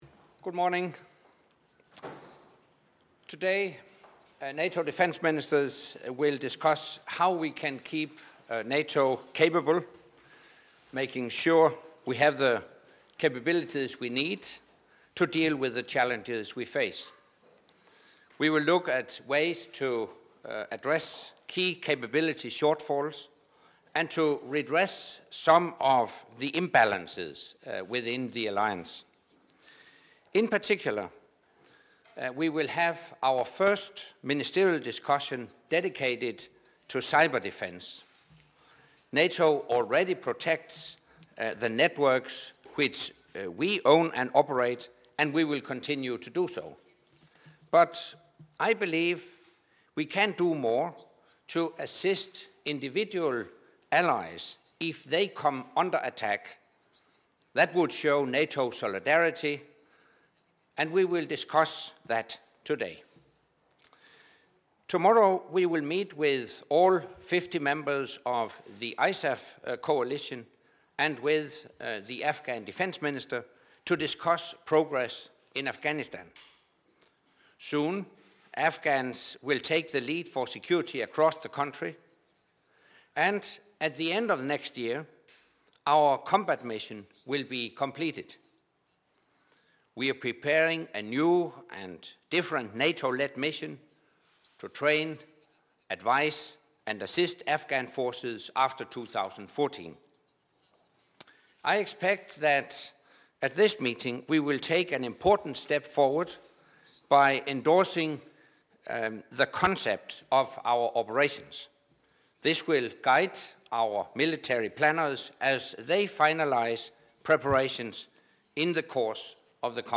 Doorstep statement by NATO Secretary General Anders Fogh Rasmussen at the start of the NATO Defence Ministers meeting